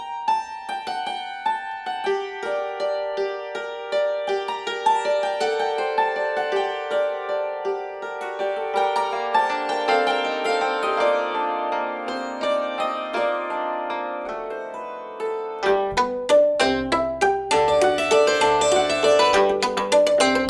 Instrumentalmusik